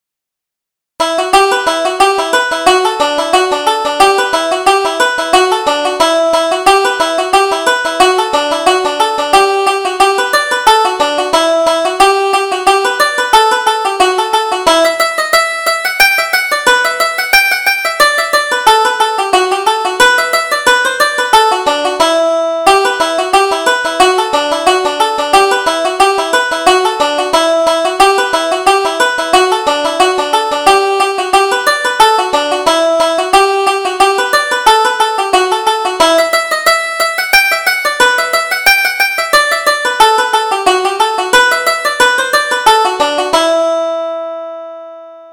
Reel: Colonel McBain